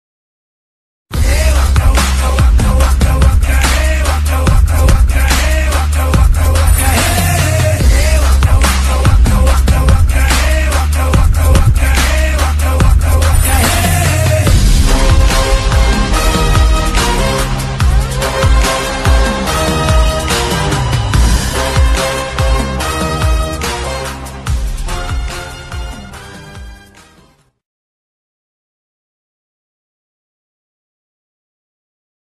Blog, Telugu Ringtones 32 Sec    update 72 Views